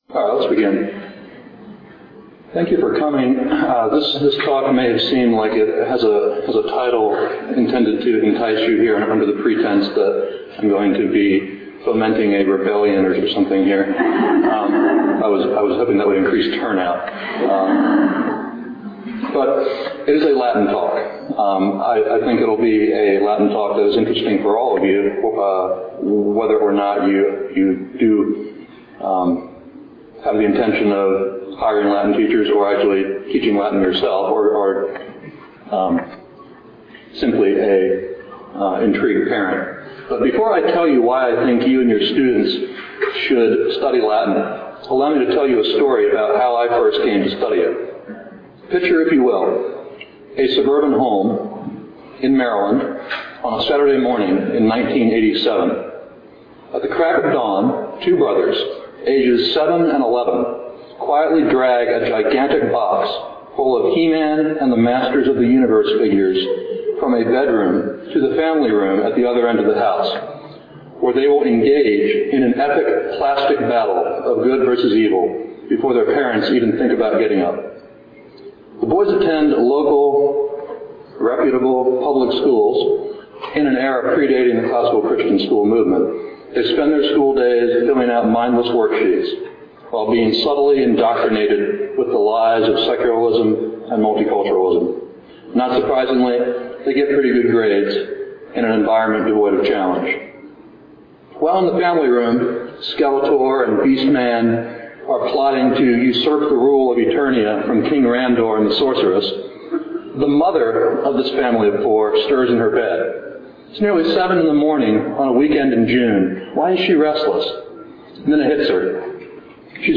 2007 Workshop Talk | 0:36:09 | All Grade Levels, Latin, Greek & Language